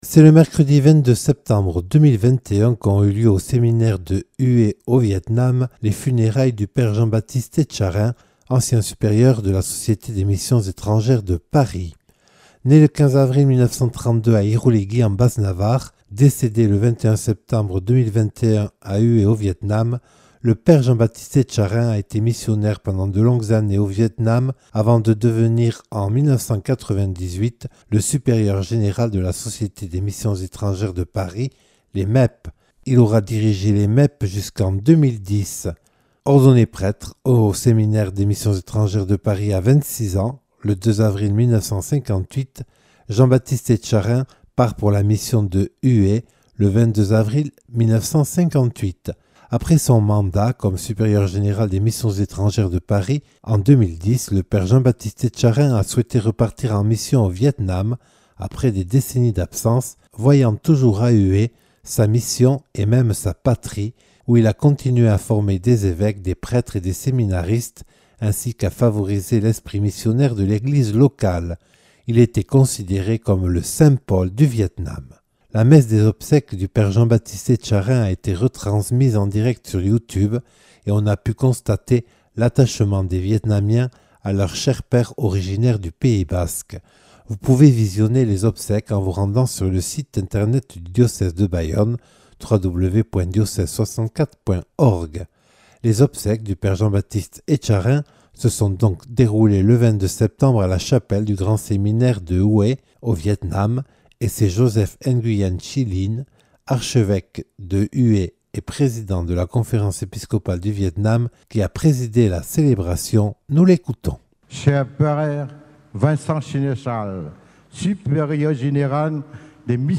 Ses obsèques ont été célébrées ce mercredi 22 septembre à 15h00 (10h00 en France) dans la chapelle du grand séminaire de Hué.
Messe des obsèques ci-dessous :